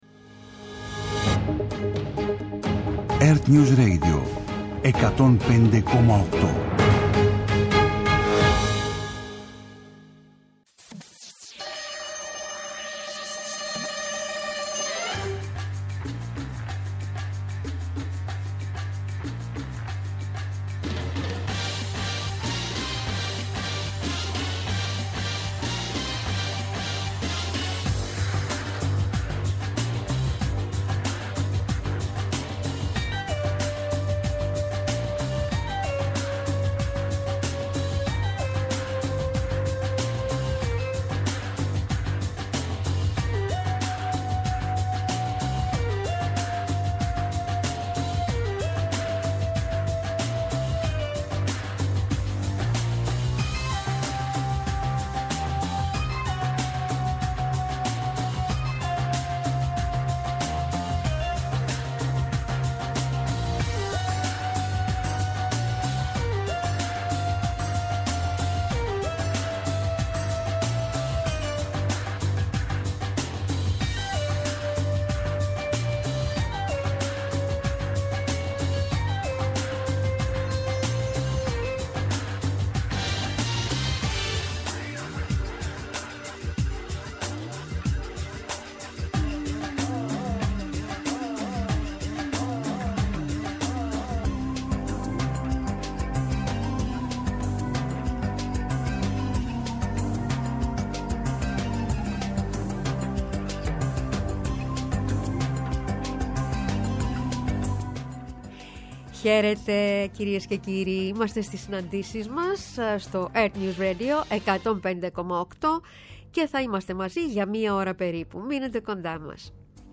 -Ο Αθανάσιος Δαββέτας, Προέδρος Εφετών – Αναπληρωτής Προϊστάμενος του Εφετείου Αθηνών το 2022 και Λογοτέχνης, σε μια συζήτηση για την έννοια της Δικαιοσύνης, αλλά και για την Λογοτεχνία και την σχέση της με την Δικαστική.